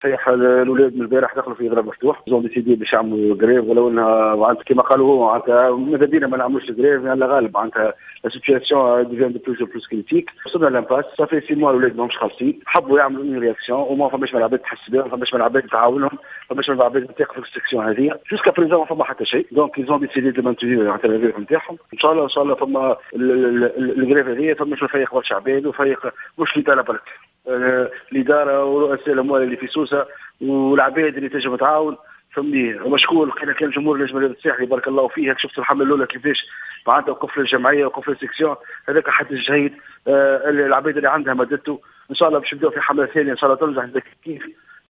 و في اتصال هاتفي